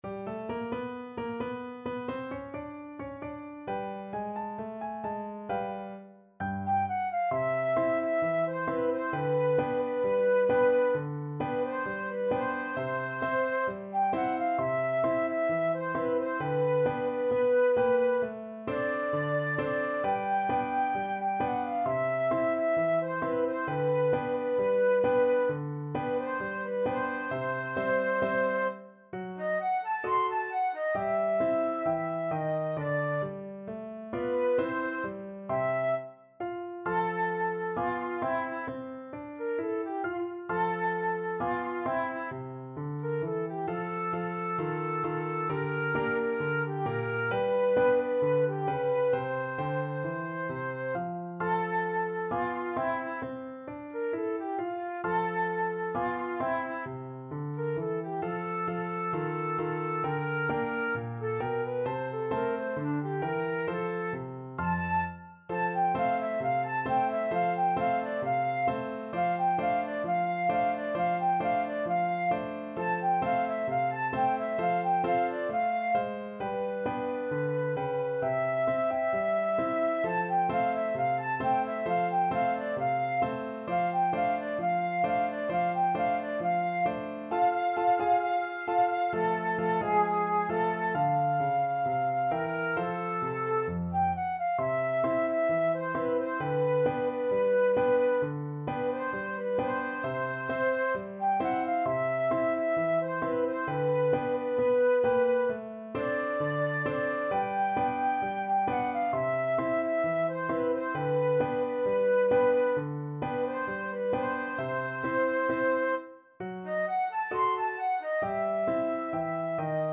Flute 1Flute 2Piano
Allegro =132 (View more music marked Allegro)
Jazz (View more Jazz Flute Duet Music)